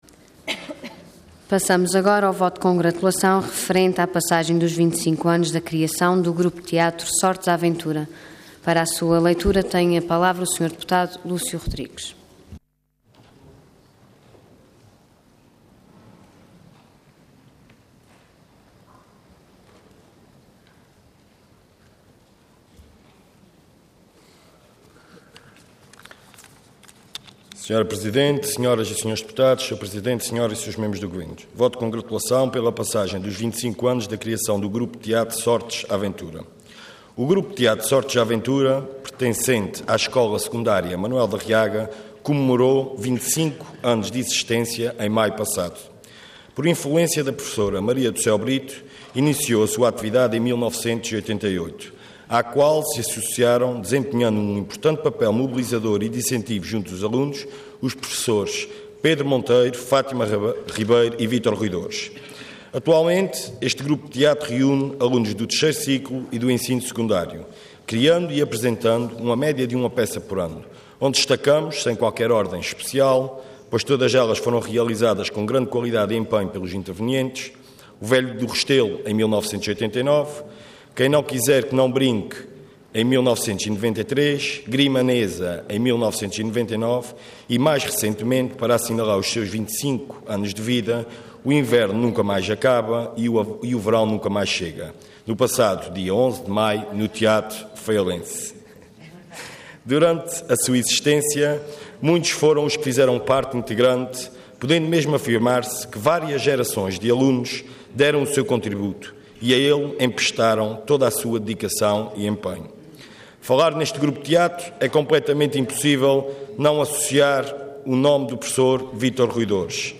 Intervenção Voto de Congratulação Orador Lúcio Rodrigues Cargo Deputado Entidade PS